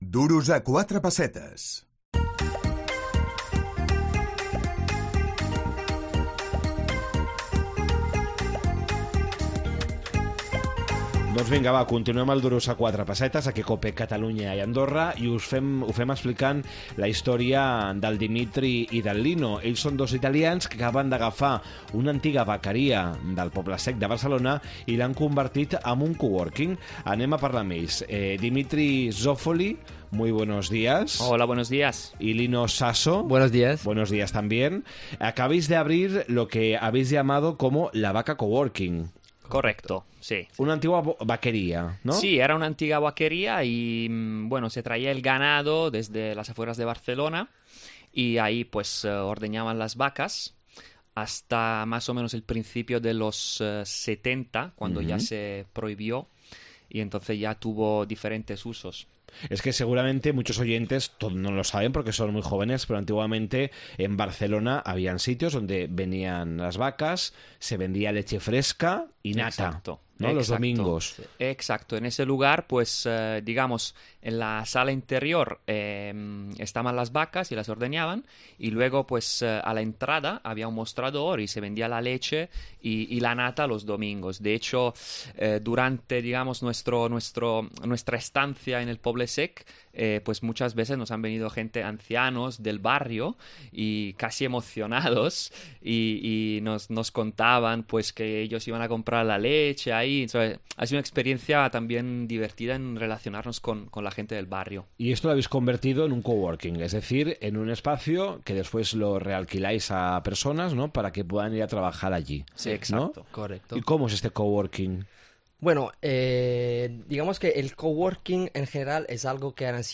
Convertir una antiga vaqueria en un coworking. La vaca coworking. Entrevista